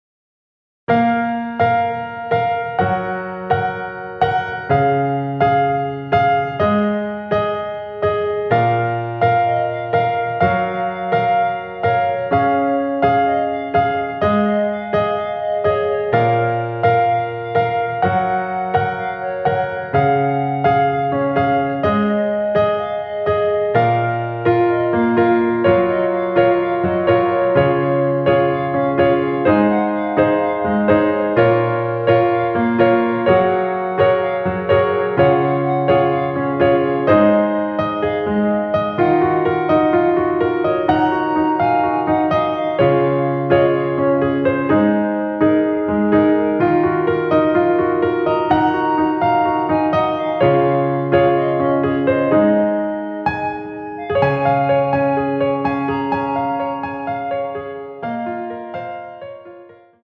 원키에서(+5)올린 멜로디 포함된 MR입니다.(미리듣기 확인)
Db
멜로디 MR이란
앞부분30초, 뒷부분30초씩 편집해서 올려 드리고 있습니다.